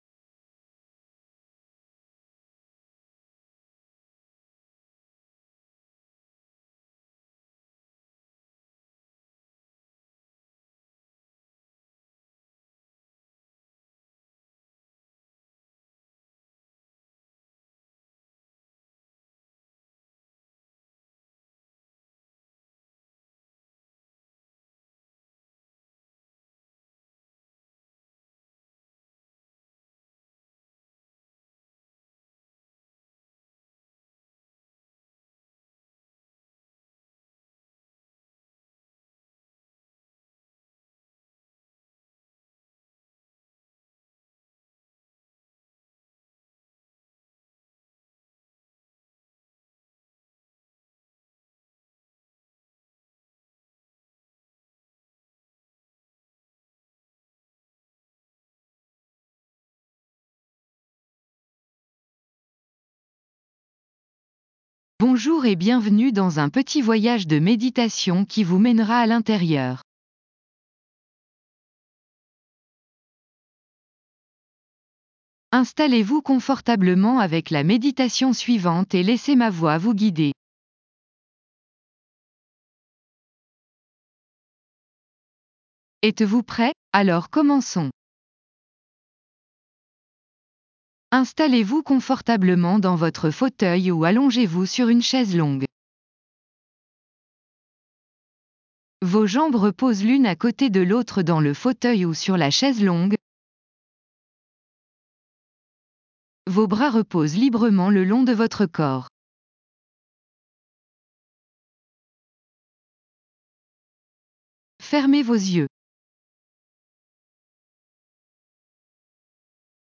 Maintenez votre concentration sur ce / ces points pendant que vous laissez la musique jouer sur vous. Il n'y a aucune affirmation ici.